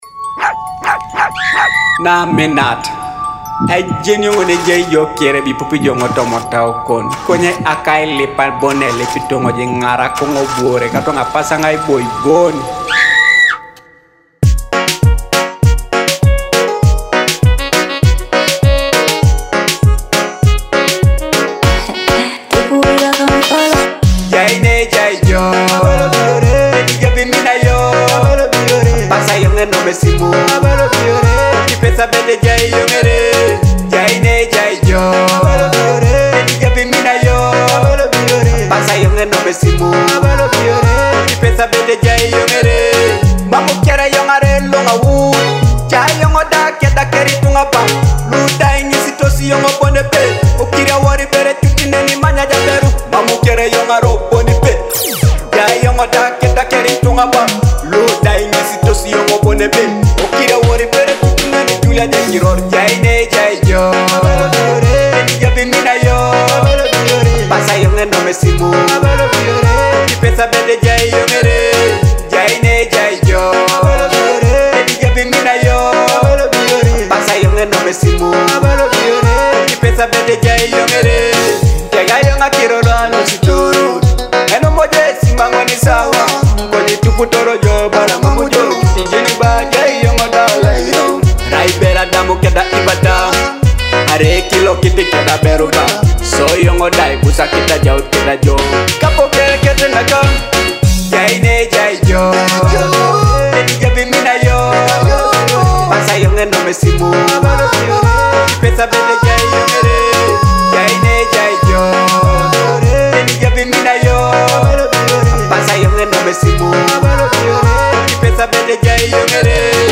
feel the heartfelt Teso love vibes in this soulful track